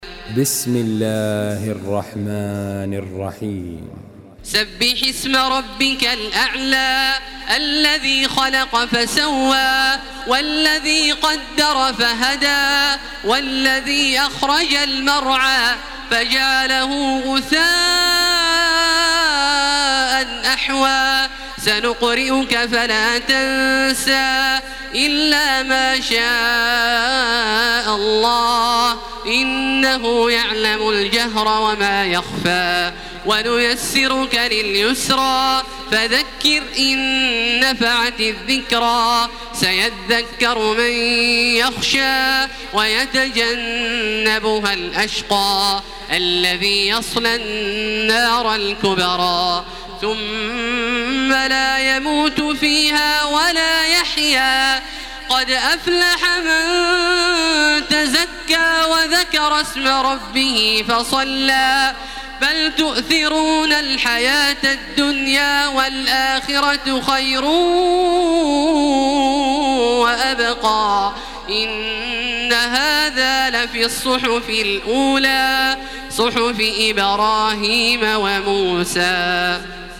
Surah Ala MP3 by Makkah Taraweeh 1433 in Hafs An Asim narration.
Murattal